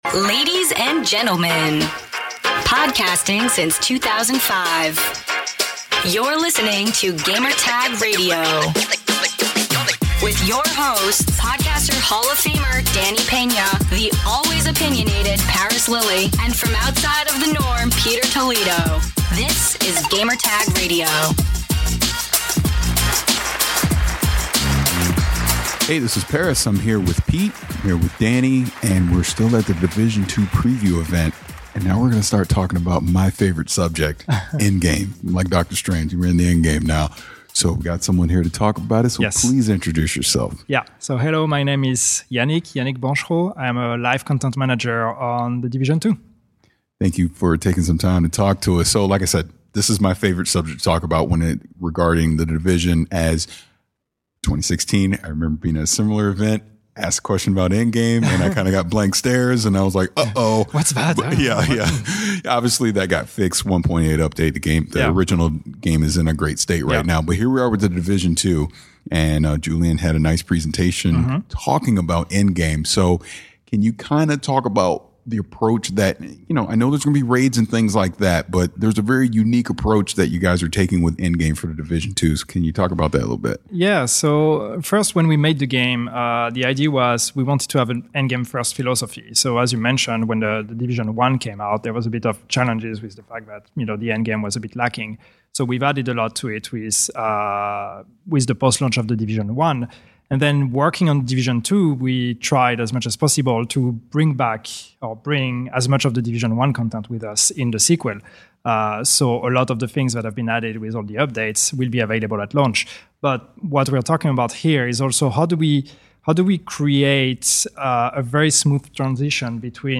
The Division 2 - End Game Interview